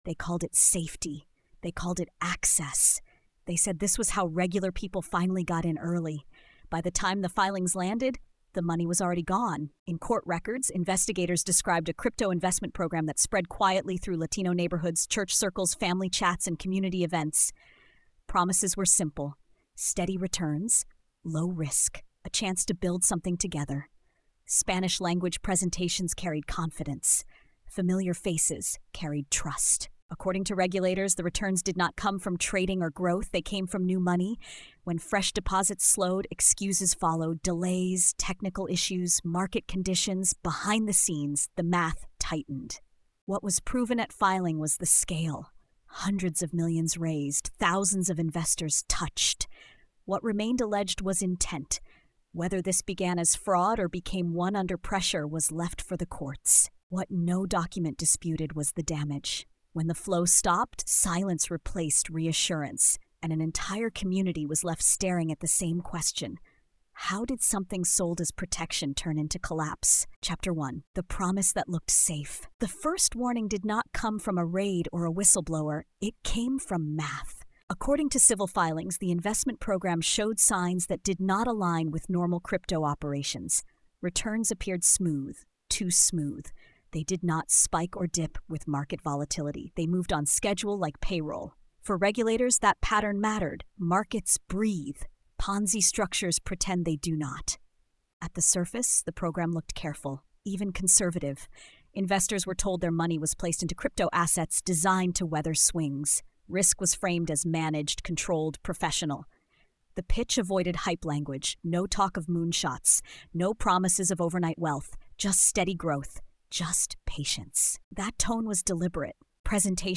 Built on regulatory filings and court records, the story traces how promises of steady returns and financial inclusion masked a structure that allegedly depended on new investor money to survive. Told in a forensic, non-sensational voice, the narrative focuses on systems, timelines, and consequences rather than spectacle, showing how opportunity language can become a tool of harm when oversight and transparency disappear.